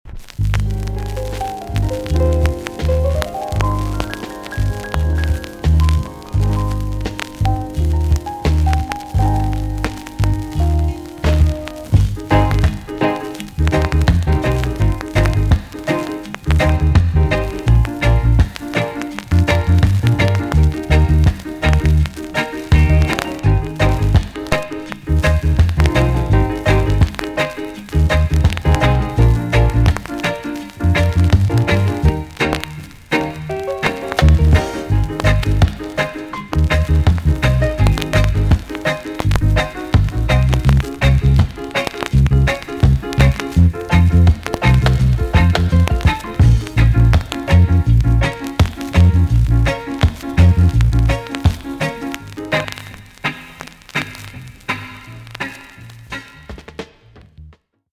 TOP >REGGAE & ROOTS
B.SIDE Version
VG+~VG ok 軽いチリノイズが入ります。